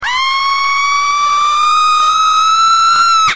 Death Scream Sound Button - Free Download & Play